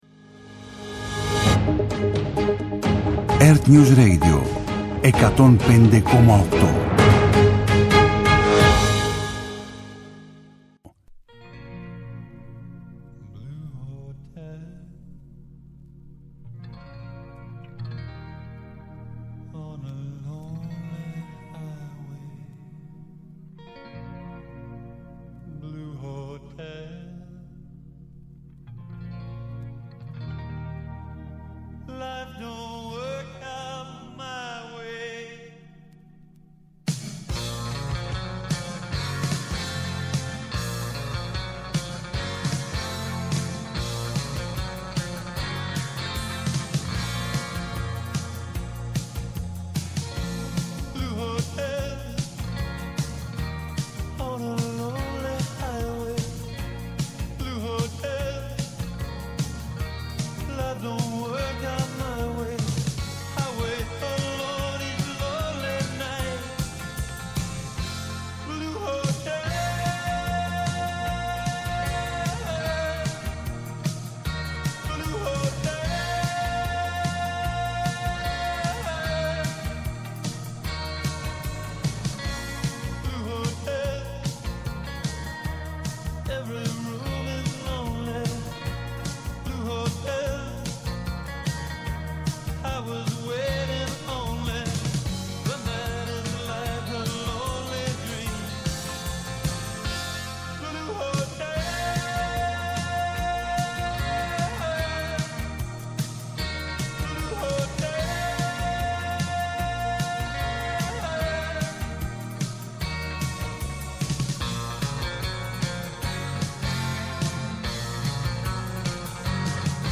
Με αναλύσεις, πρακτικές συμβουλές και συνεντεύξεις με πρωτοπόρους στην τεχνολογία και τη δημιουργικότητα, τα «Ψηφιακά Σάββατα» σας προετοιμάζει για το επόμενο update.